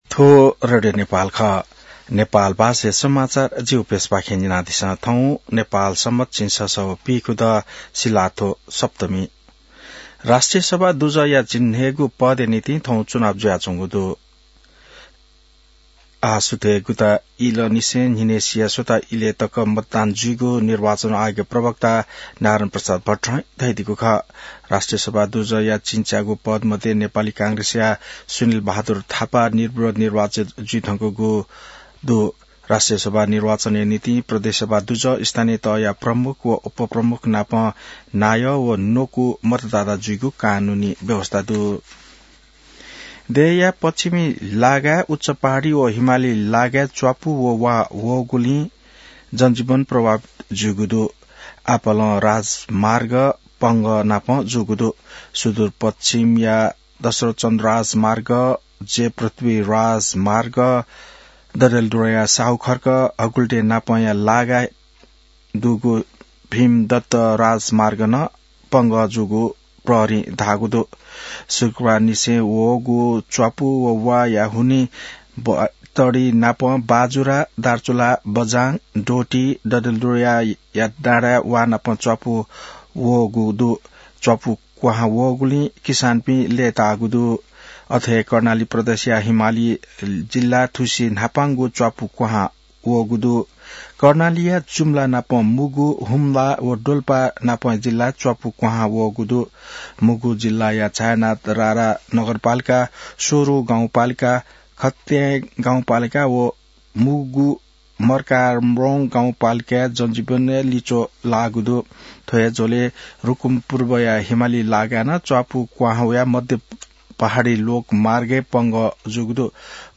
नेपाल भाषामा समाचार : ११ माघ , २०८२